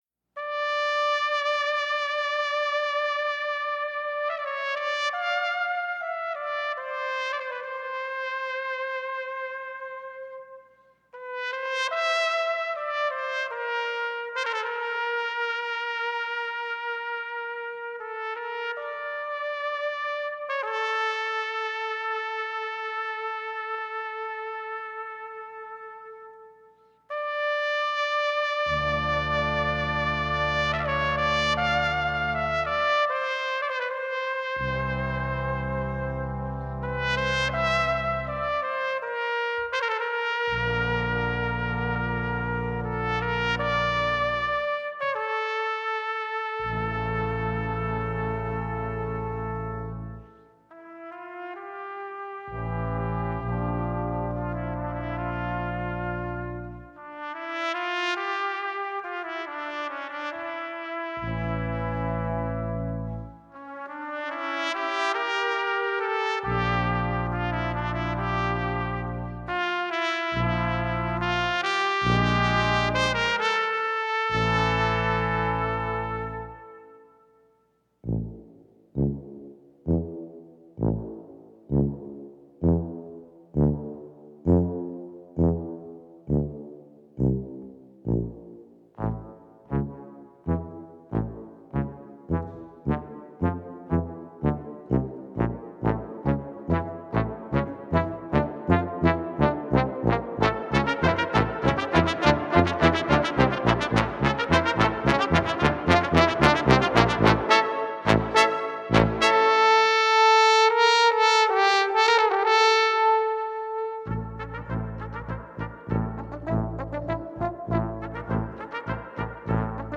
The opening sounds like The Godfather soundtrack.